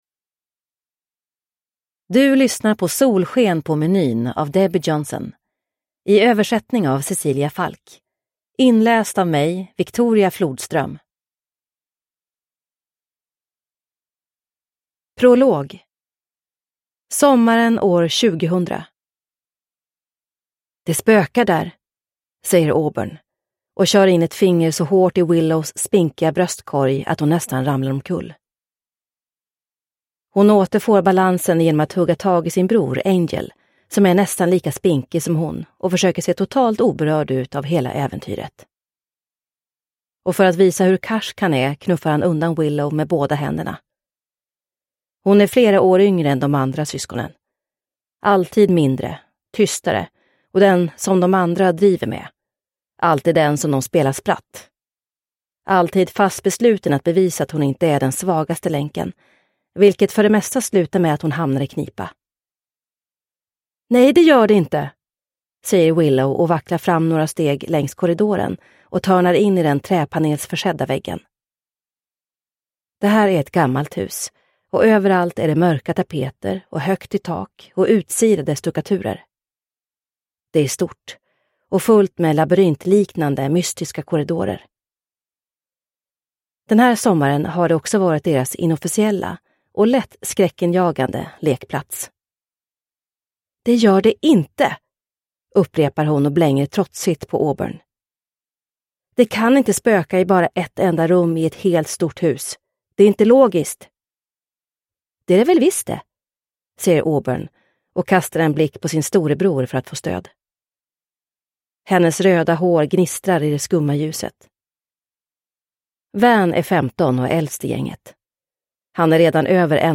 Solsken på menyn – Ljudbok – Laddas ner